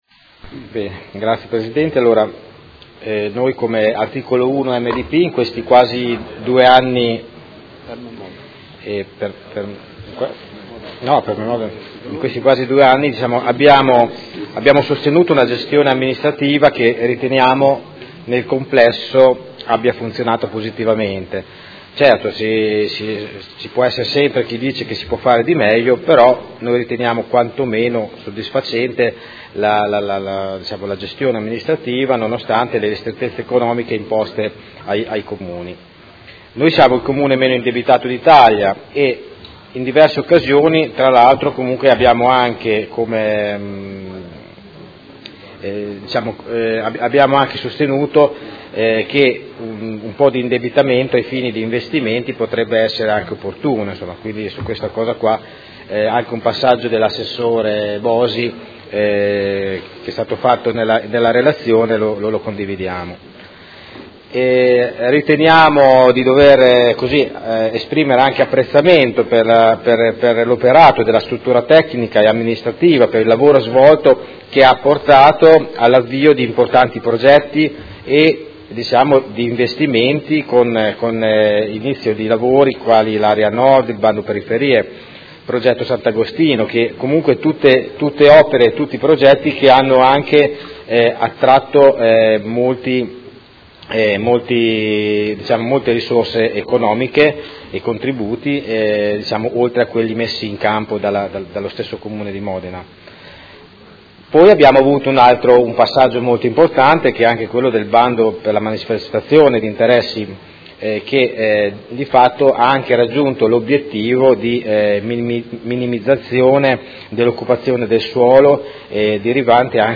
Seduta del 20/12/2018. Dichiarazioni di voto su delibera di bilancio, Ordini del Giorno, Mozioni ed emendamenti